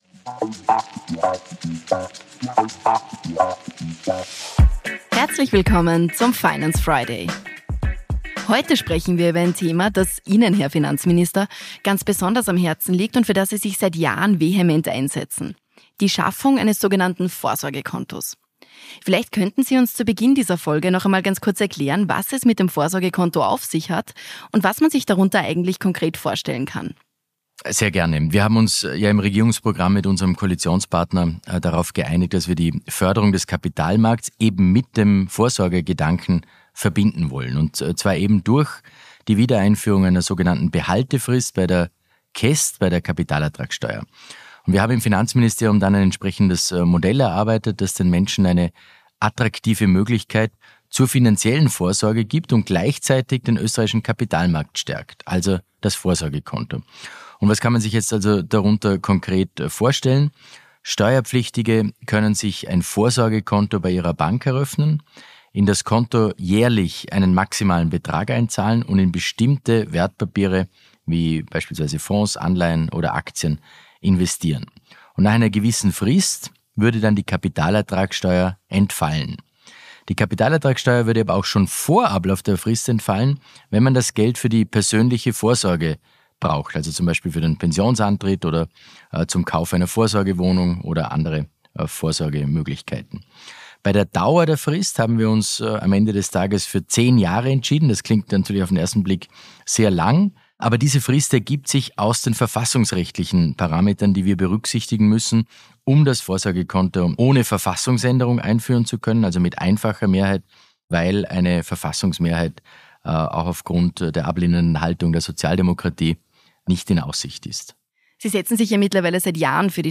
In der aktuellen Folge des „Finance Friday” spricht Finanzminister